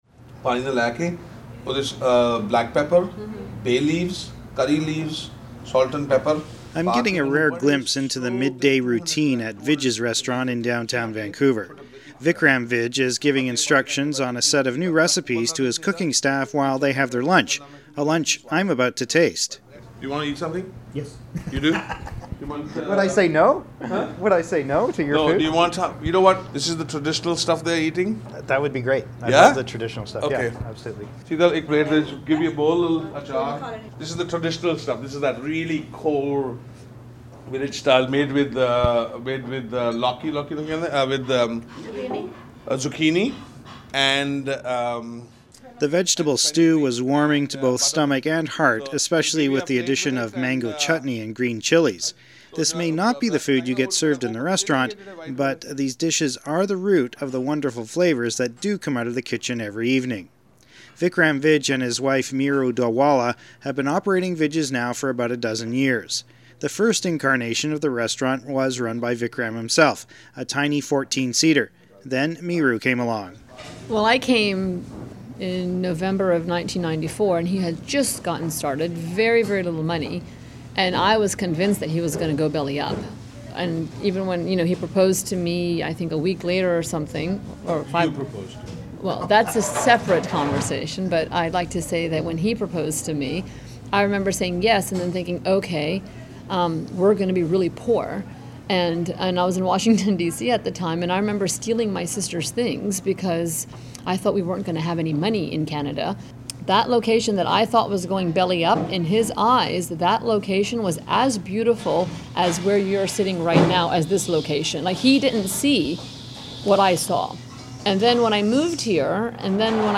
audio file of today’s documentary.